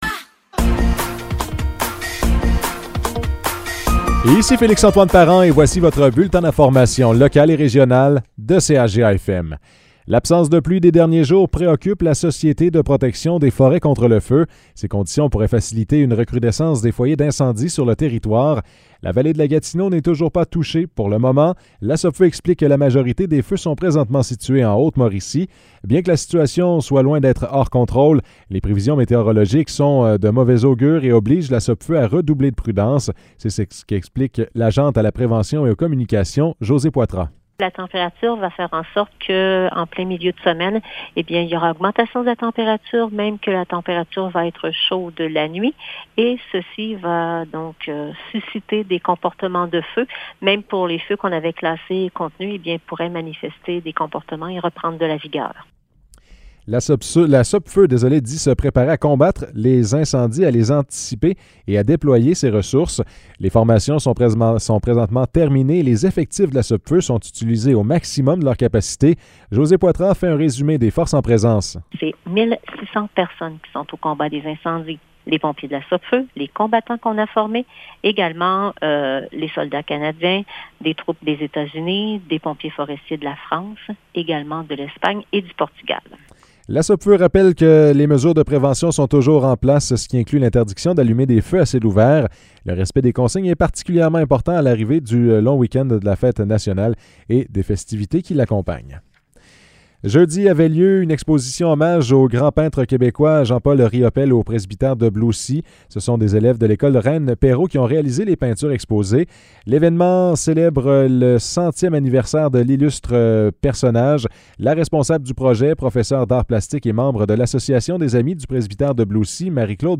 Nouvelles locales - 19 juin 2023 - 15 h